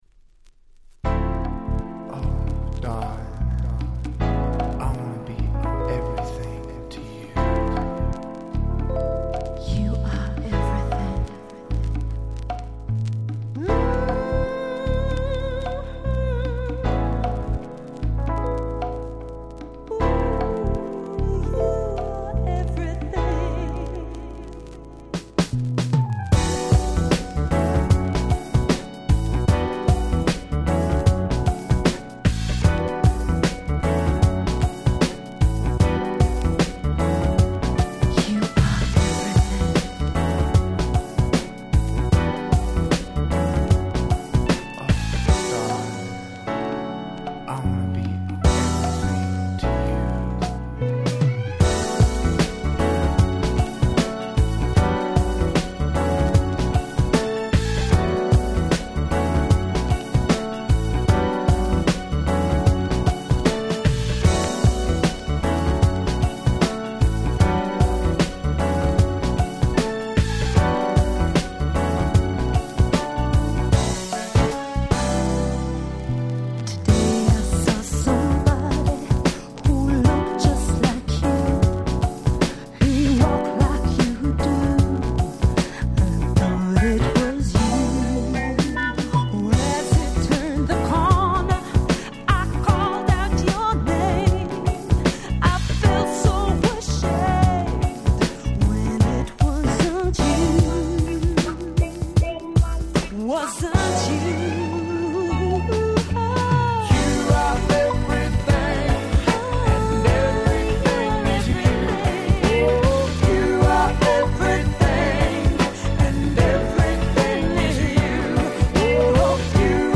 【Media】Vinyl 12'' Single
94' Nice Cover R&B !!